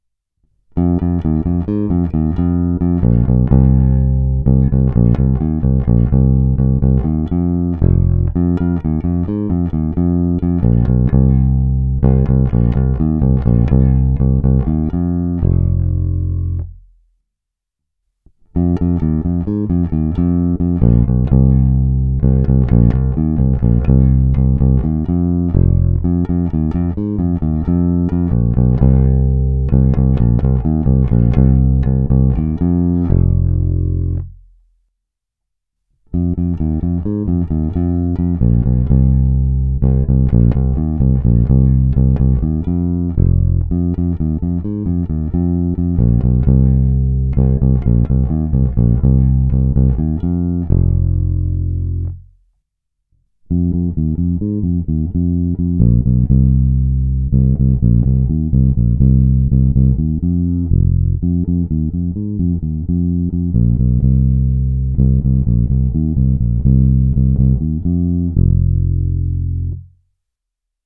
Zvuk je typický Precision.
Není-li uvedeno jinak, následující nahrávky jsou vyvedeny rovnou do zvukové karty, vždy s plně otevřenou tónovou clonou, a kromě normalizace ponechány bez úprav.
Slap